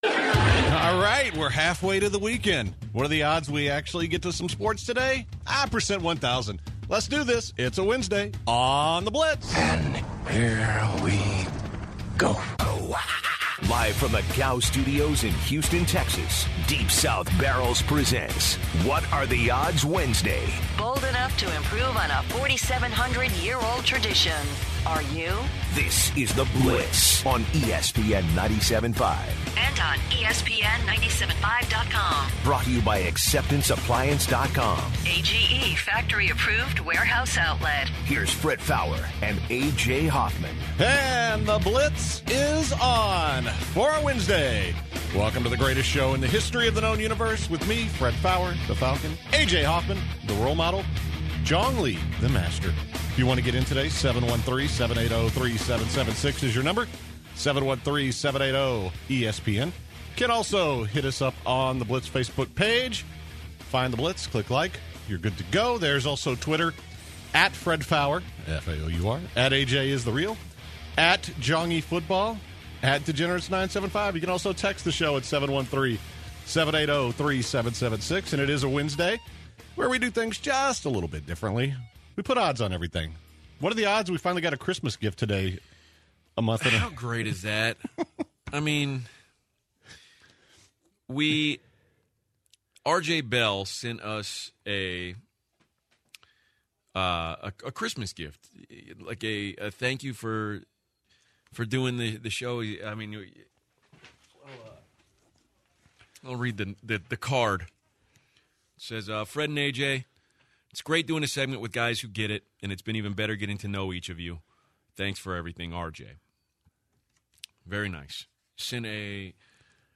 The guys then take a few Odds calls and discuss who their potential MVP votes would be for the NBA this year.